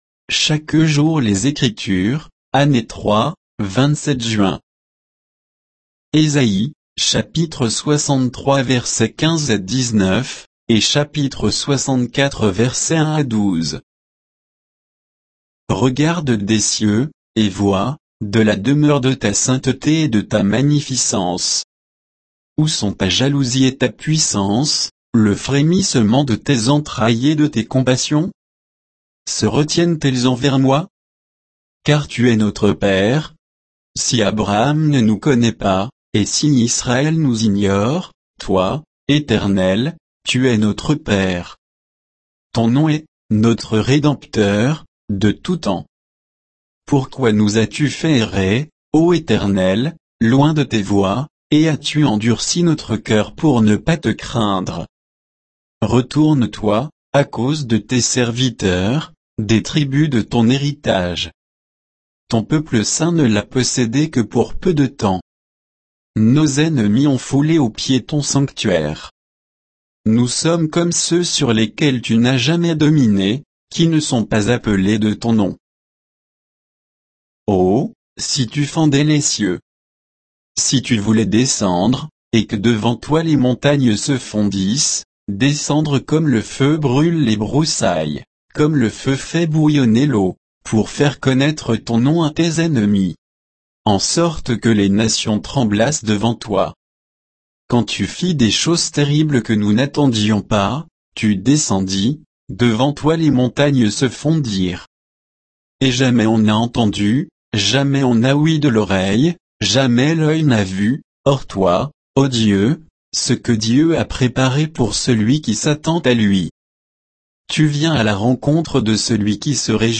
Méditation quoditienne de Chaque jour les Écritures sur Ésaïe 63